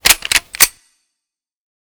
SFX_Equip Assault Rifle.wav